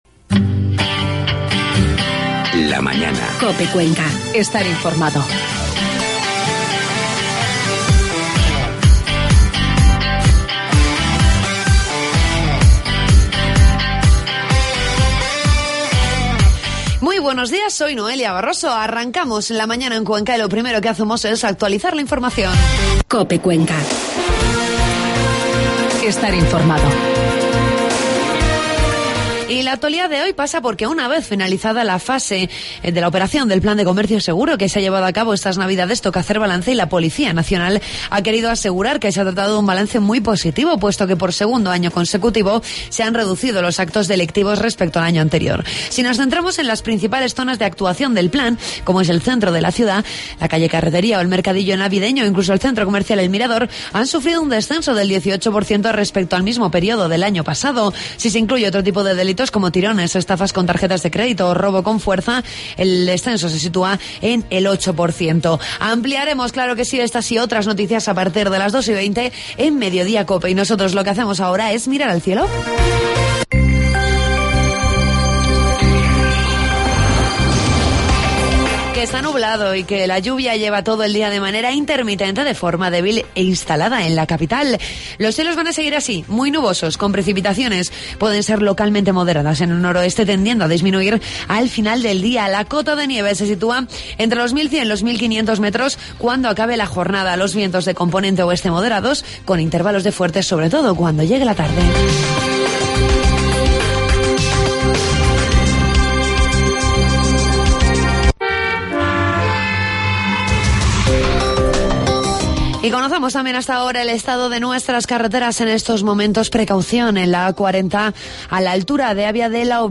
La Mañana en Cuenca martes 14 de enero de 2014. Entrevistamos al diputado regional, Vicente Giménez, con el hablamos de diversos asuntos de interés en la región.